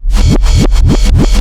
REVERSBRK3-L.wav